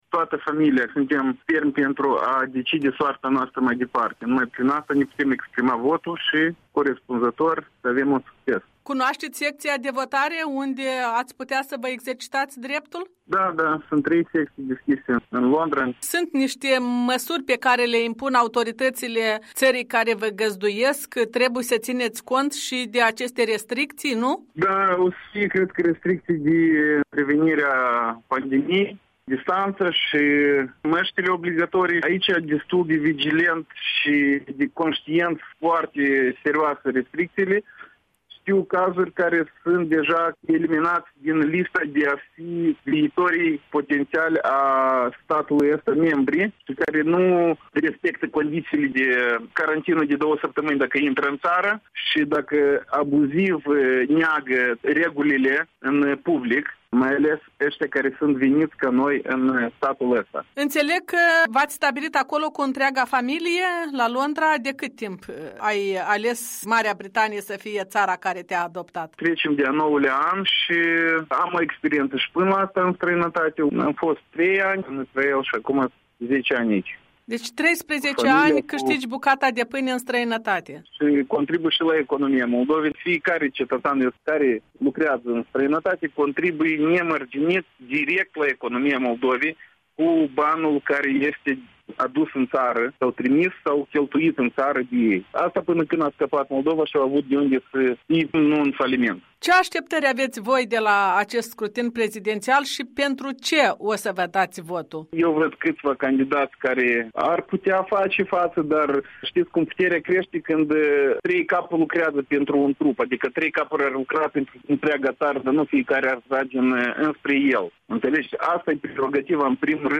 Interviu electoral